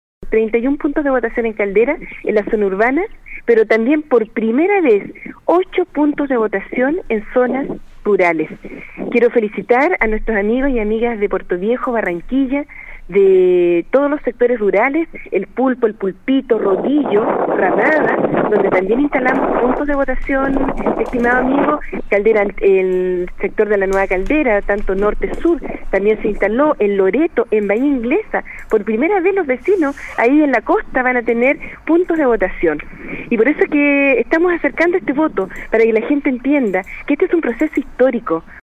Desde la comuna de Caldera, en contacto con Nostálgica, la alcaldesa de Caldera, Brunilda González, comentó que el proceso hasta el momento se desarrolla de manera tranquila, y a la vez hizo un llamado a los ciudadanos a acercarse a votar. Destacó la cantidad de puntos de votación habilitados e instó a ayudar a las personas con problemas de movilidad para que puedan votar.